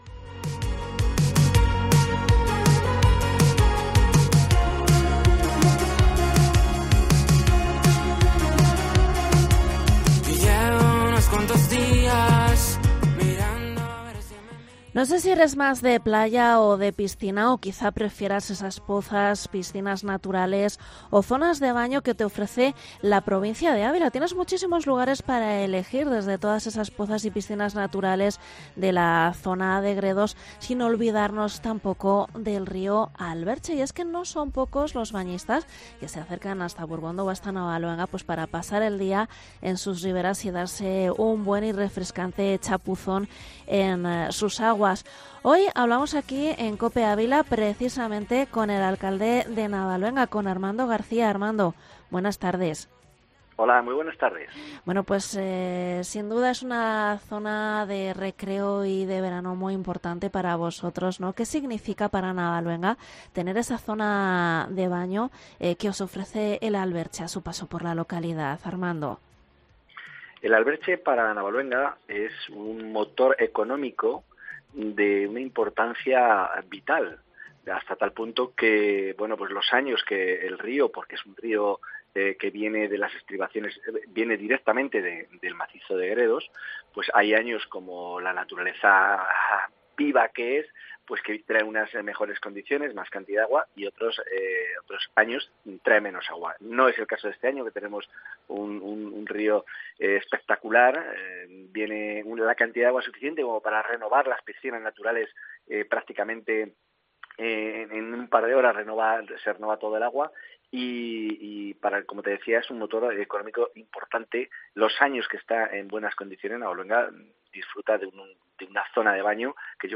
Entrevista alcalde de Navaluenga, Armando García. Río Alberche a su paso por el pueblo